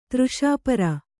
♪ třṣāpara